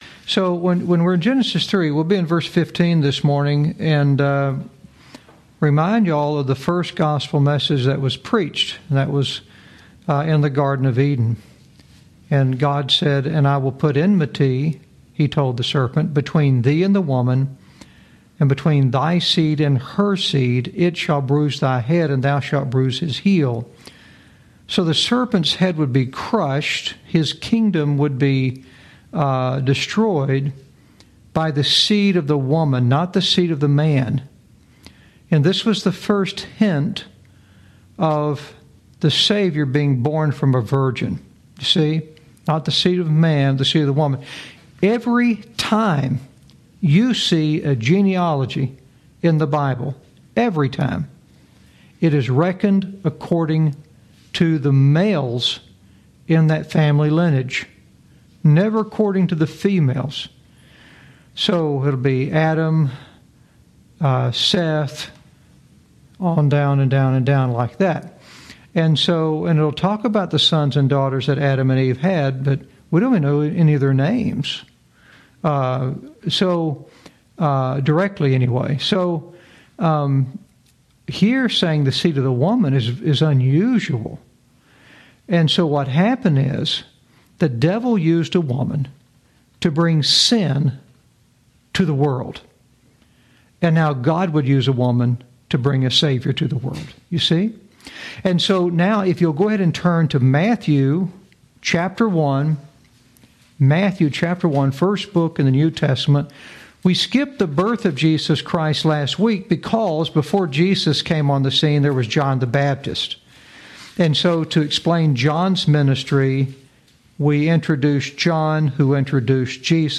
Lesson 34